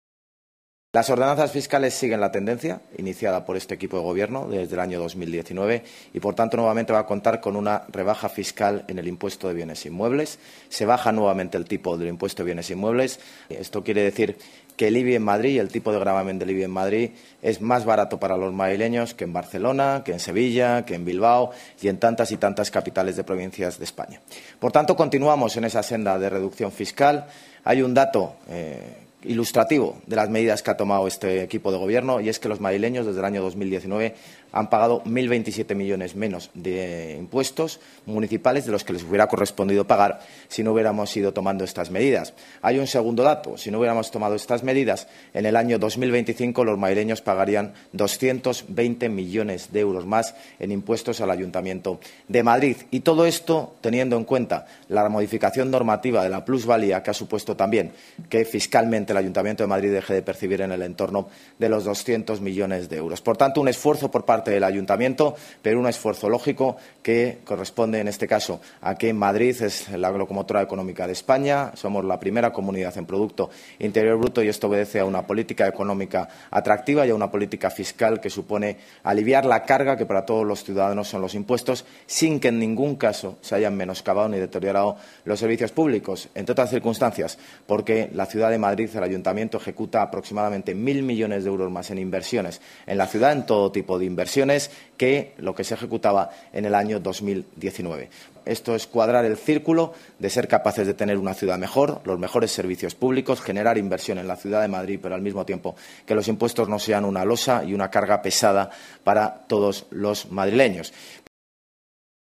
Nueva ventana:El alcalde de Madrid, José Luis Martínez-Almeida, duranta la rueda de prensa